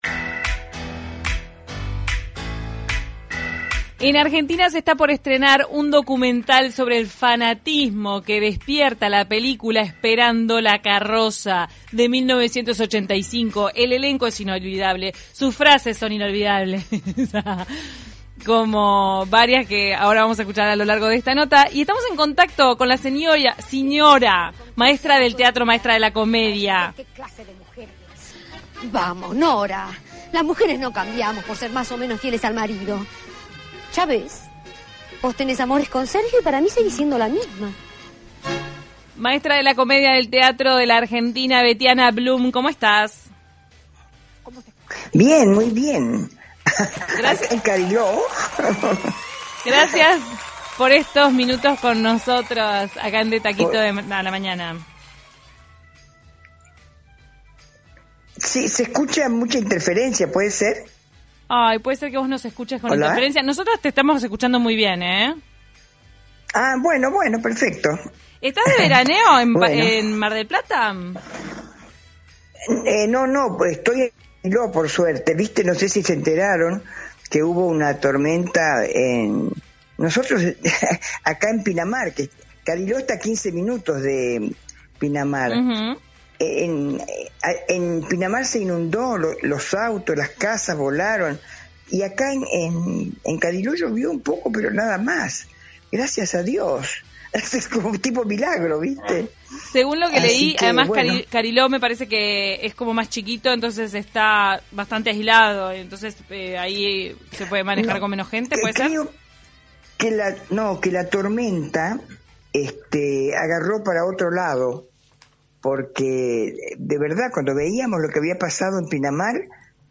De taquito a la mañana dialogó con la actriz Betiana Blum.